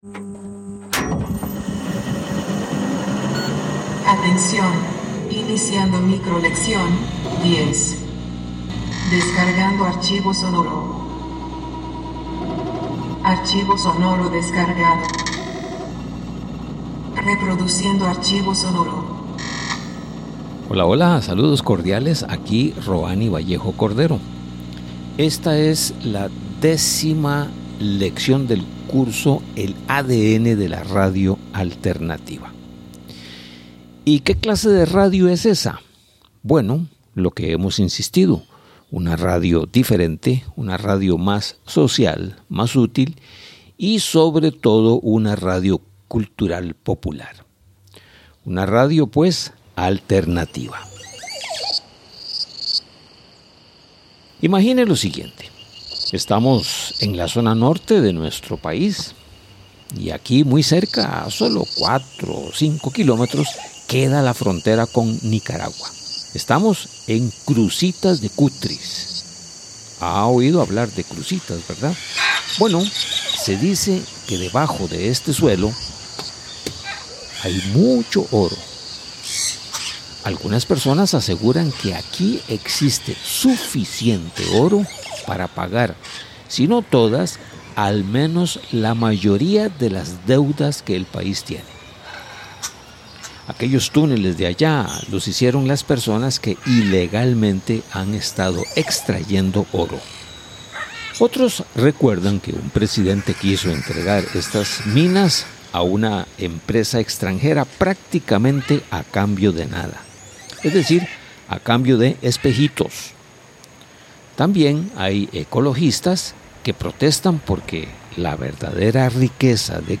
Esta es la micro-lección 10 del curso titulado: El ADN de la radio alternativa.